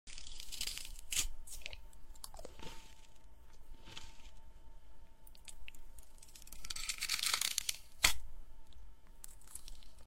Crispy Chip Bite ASMR 🔊 sound effects free download
Crispy Chip Bite ASMR 🔊 | Satisfying Close-Up Hear every crunch. 🔊 Watch this close-up chip bite in perfect detail — crisp, clean sound and pure ASMR satisfaction you can feel.